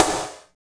47.08 SNR.wav